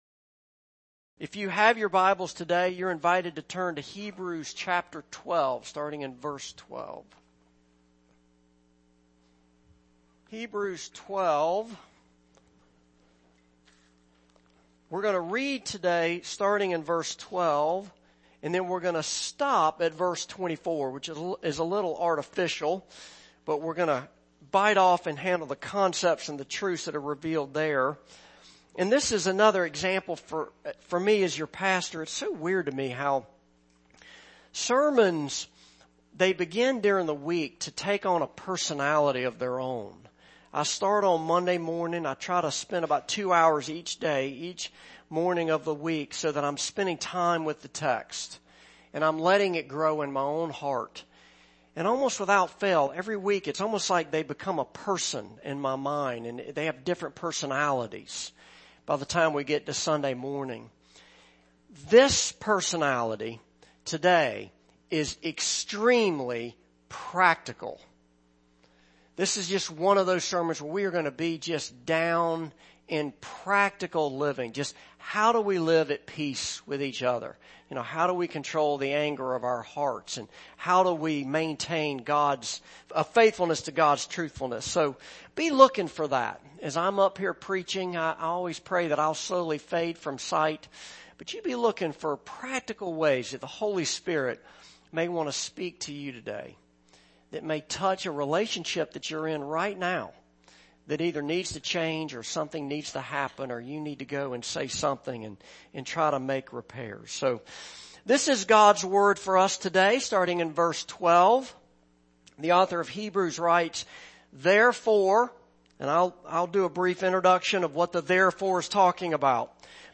Passage: Hebrews 12:12-24 Service Type: Morning Service